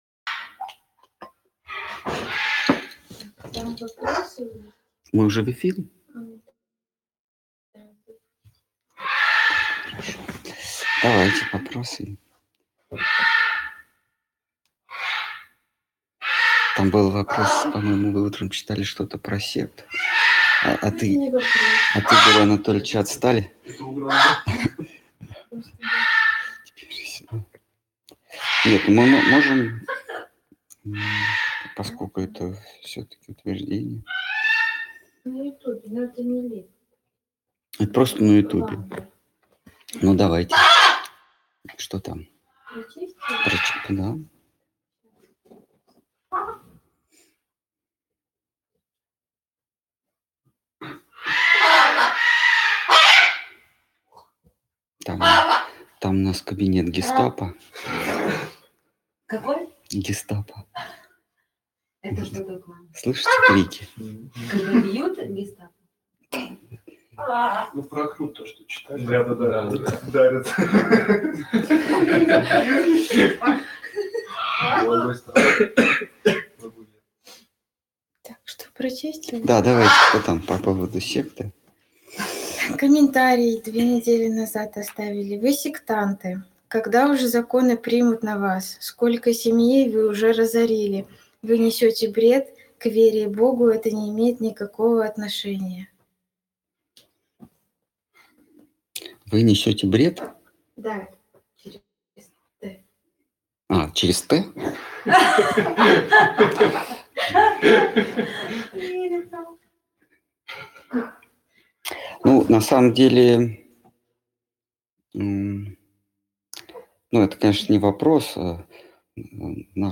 Ответы на вопросы из трансляции в телеграм канале «Колесница Джаганнатха».